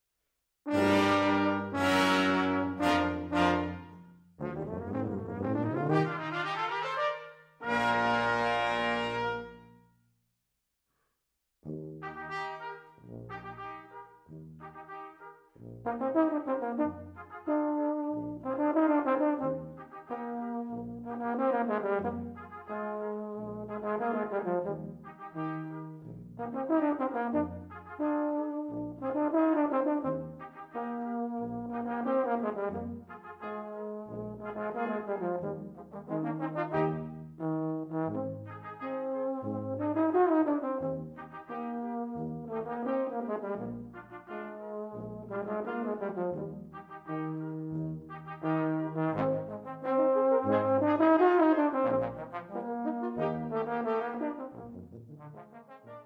For Euphonium Solo
with Brass Quintet.